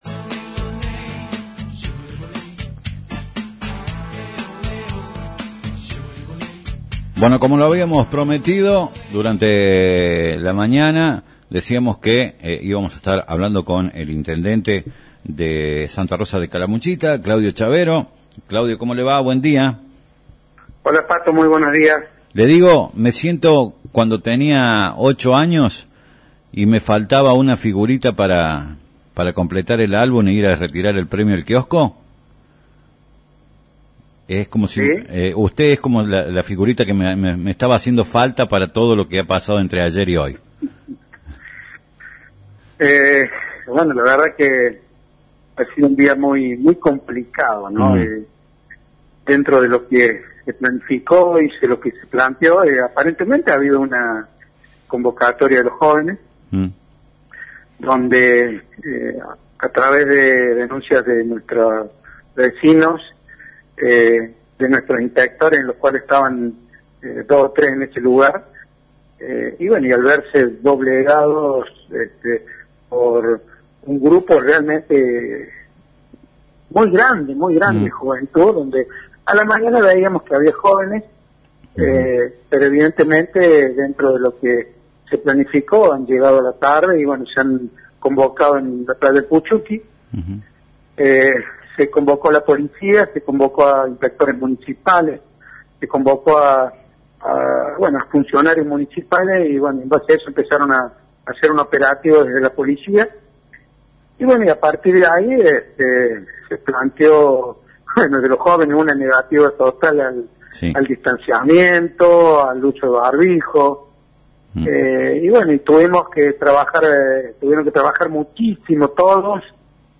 El intendente de Santa Rosa de Calamuchita dialogó con la radio que es parte de tu vida, se refirió a los hechos acontecidos en el dia de ayer, con los jóvenes amontonados en los Balnearios de nuestra ciudad, habló de los jóvenes mantuvieron una negativa ante el pedido de la policía y de las autoridades municipales, dijo que mantiene una comunicación seguida con el gobernador de Córdoba para ir transmitiéndole lo que sucede de cara a lo que viene.